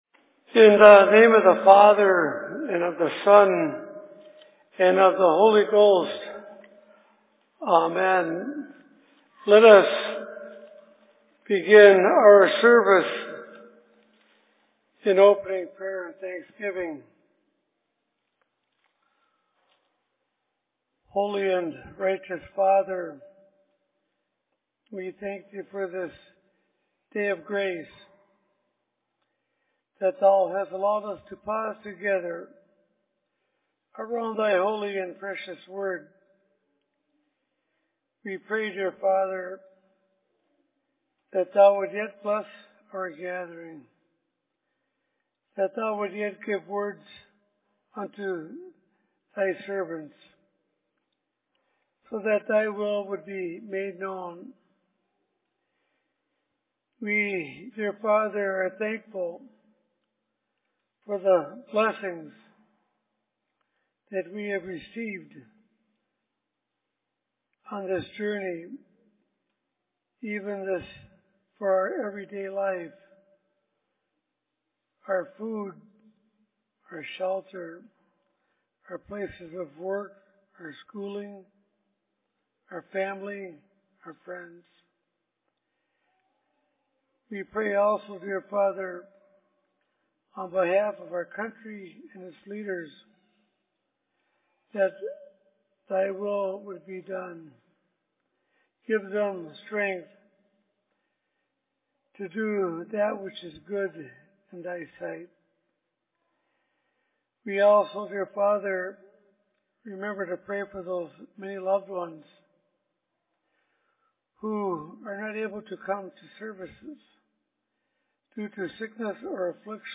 Sermon in Toronto 19.11.2016
Location: LLC Toronto